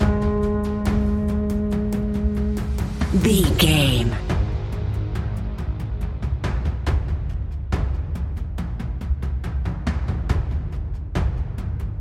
Atmospheric Slow Suspense Stinger.
Aeolian/Minor
G#
ominous
dark
haunting
eerie
synthesiser
drums
horror music